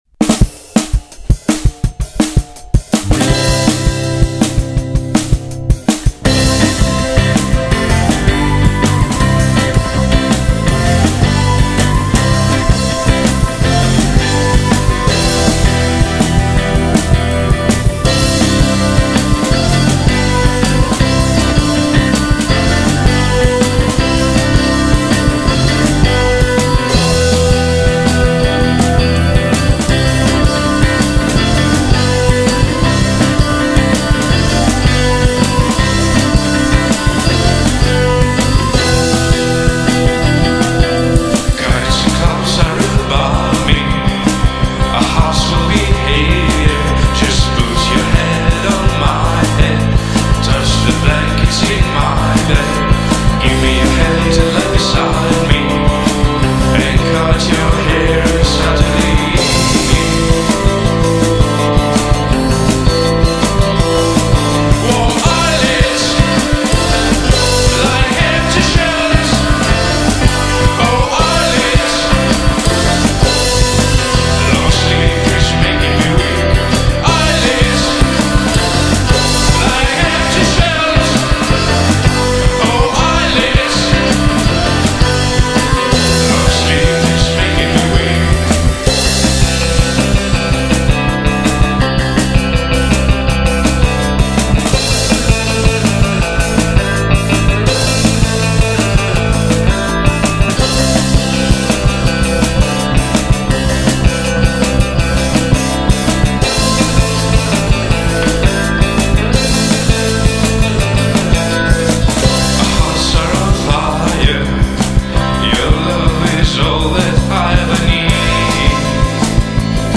new-wave ispirata in buona parte ai Joy Division
voce baritonale